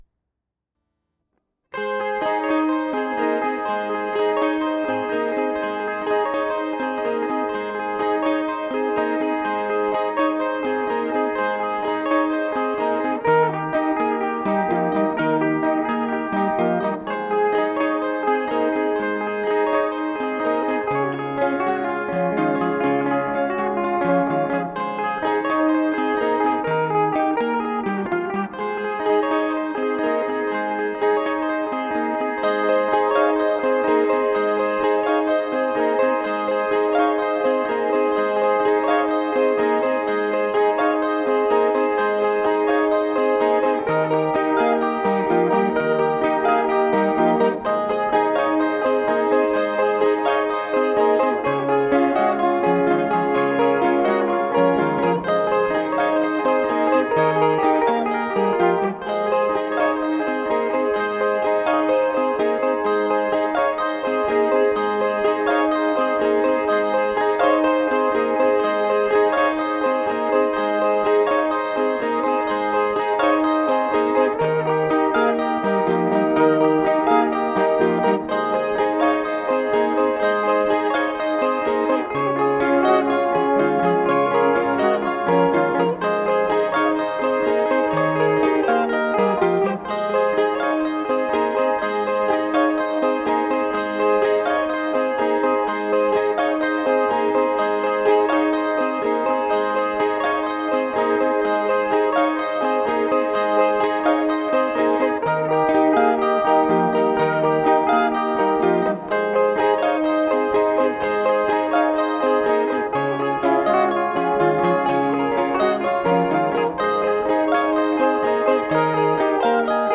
12-string guitar orchestra